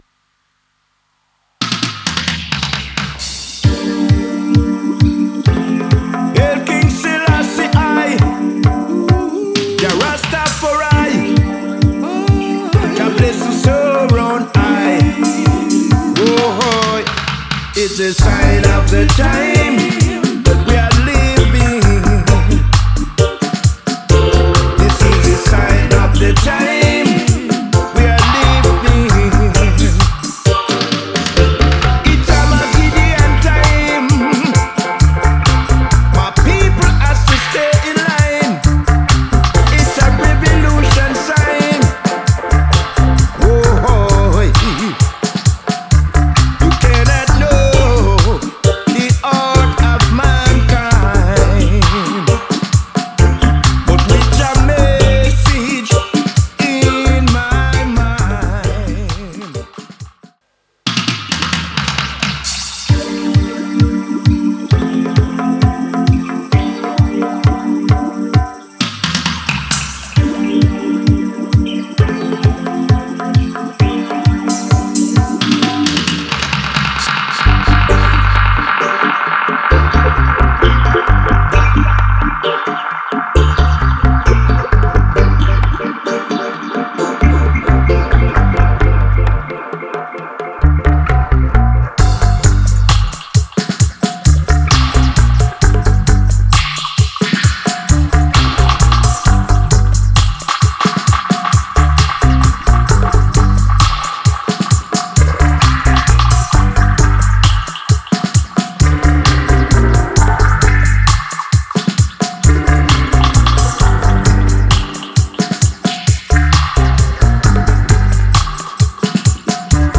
Dubs